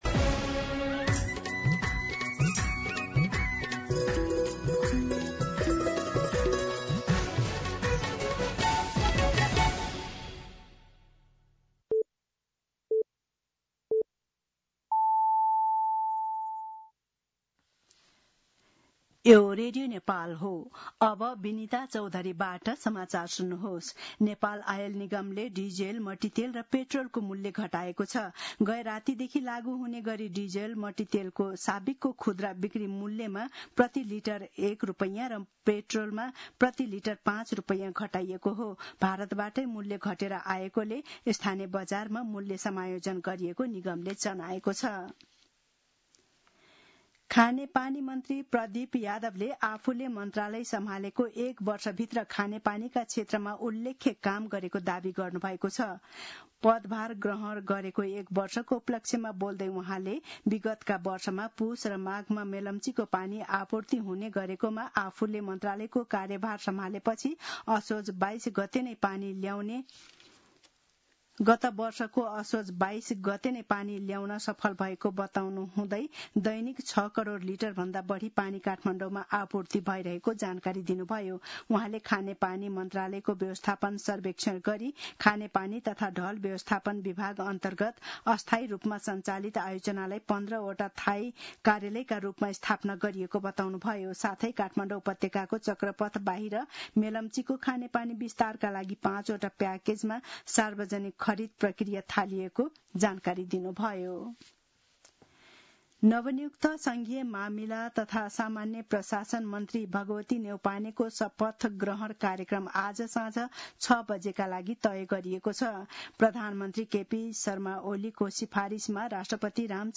दिउँसो १ बजेको नेपाली समाचार : ३२ असार , २०८२